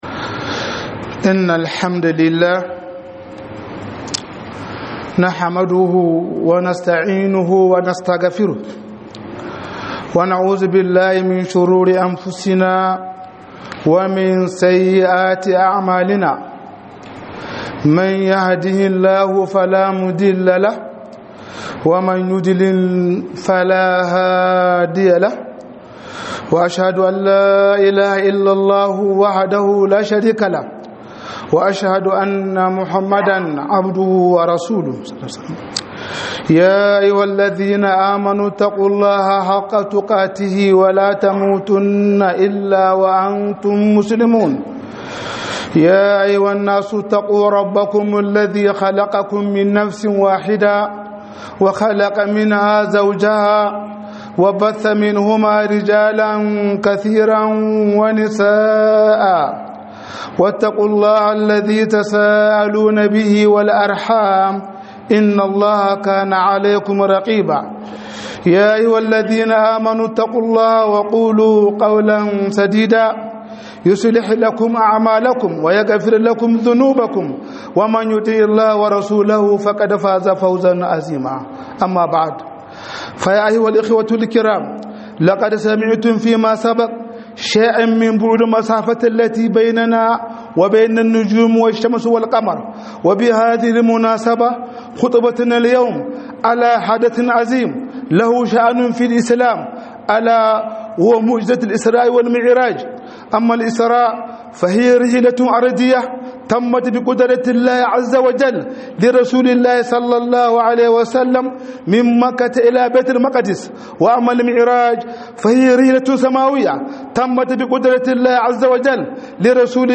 001 ISRA'I DA MI'IRAJI - HUDUBA